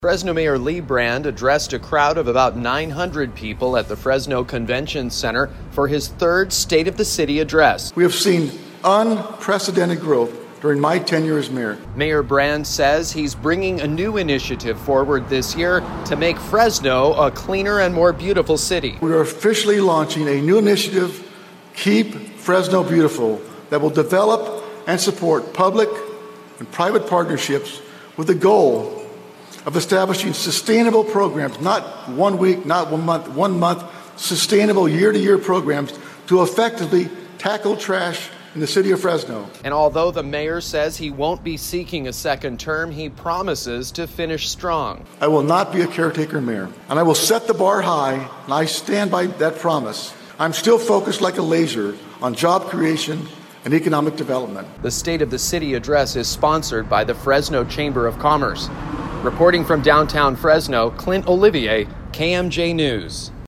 Mayor Lee Brand speaks to a crowd of 900 during his third State of the City address.